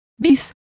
Complete with pronunciation of the translation of encore.